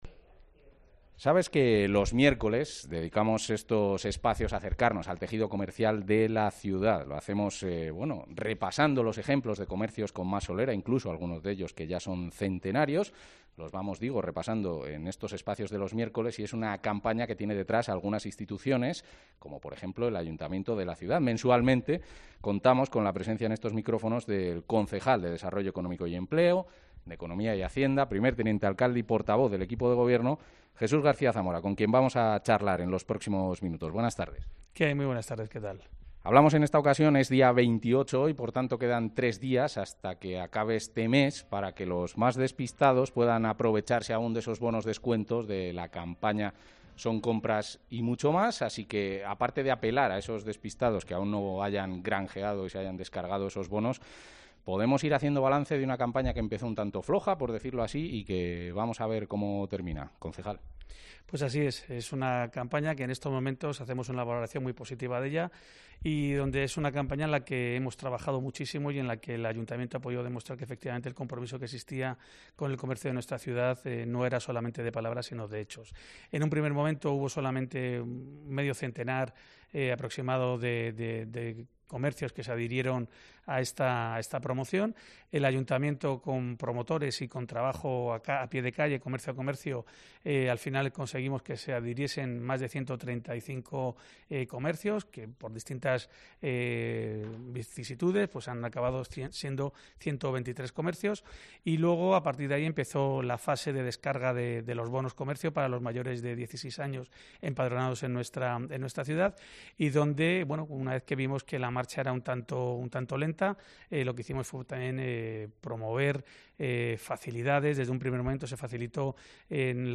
Escucha la valoración del concejal de Economía y Hacienda y de Desarrollo Económico y Empleo, primer teniente alcalde y portavoz del equipo de gobierno, Jesús García Zamora